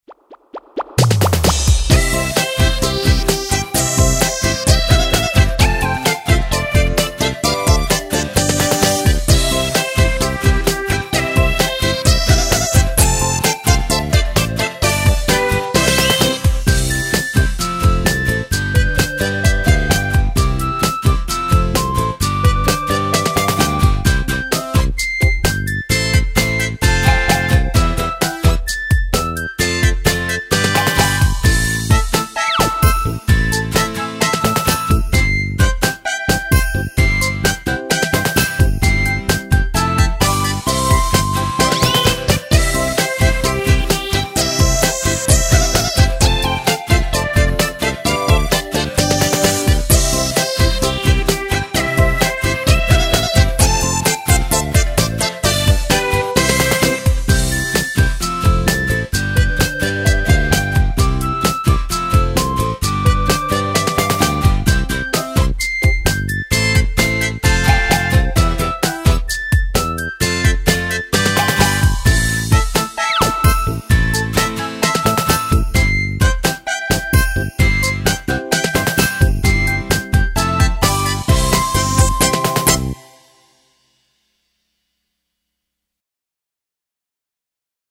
猴仔(純伴奏版) | 新北市客家文化典藏資料庫